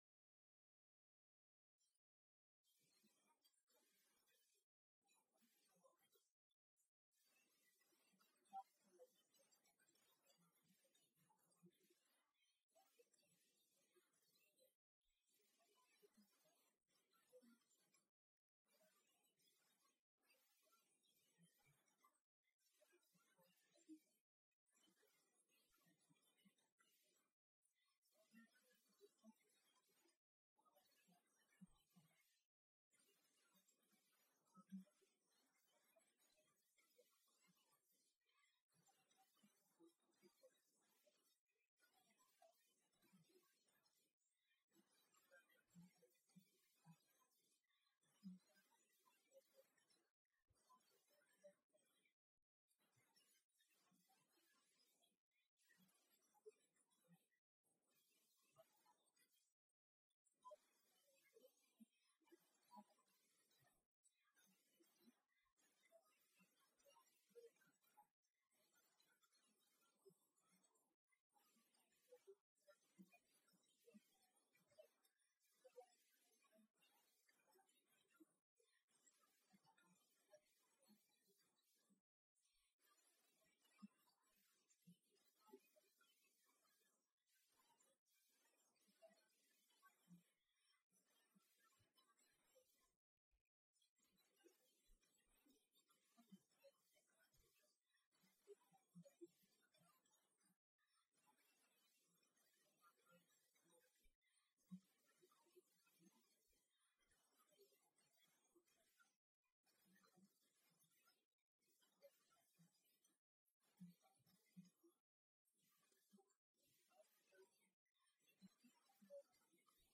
Аудиокнига Неукушенный локоть | Библиотека аудиокниг